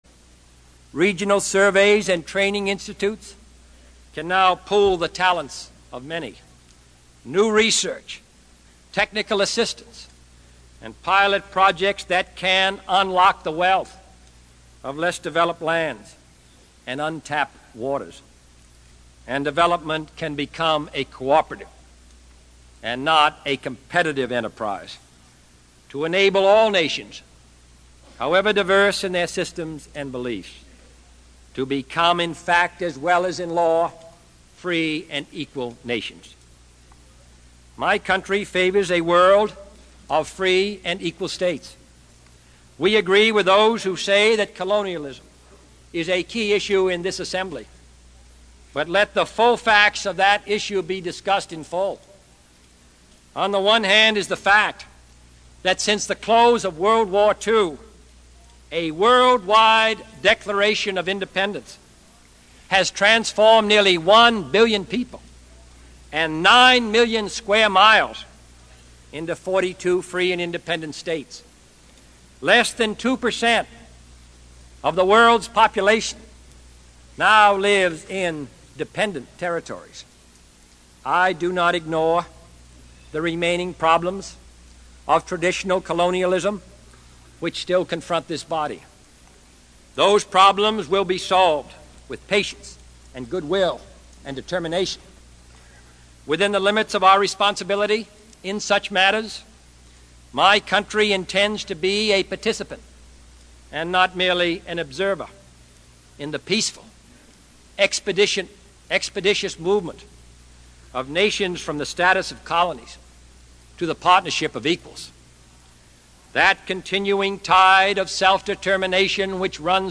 John F Kennedy Address to United Nations 7
Tags: John F. Kennedy John F. Kennedy Address United Nations John F. Kennedy speech President